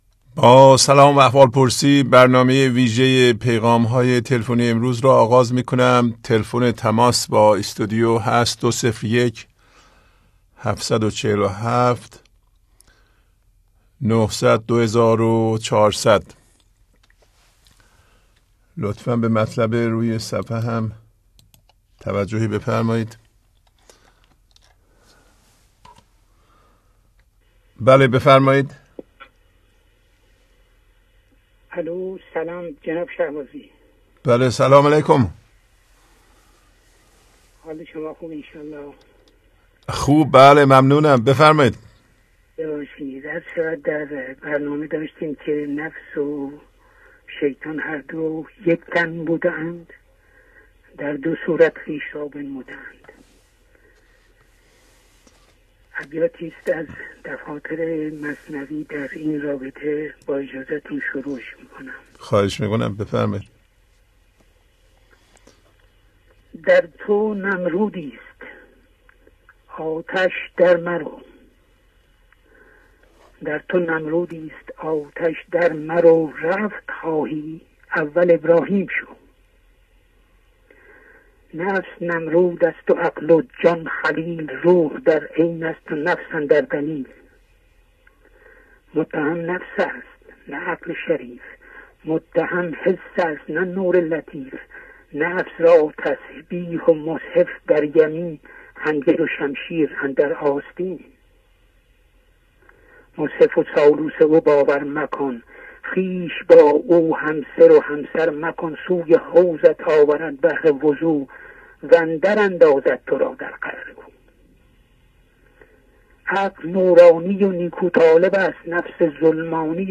Phone Calls Audio Programs #1038-2.